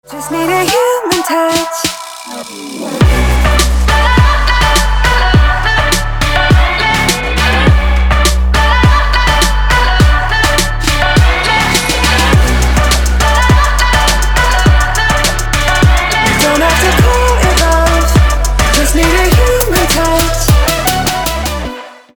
• Качество: 320, Stereo
dance
Electronic
EDM
club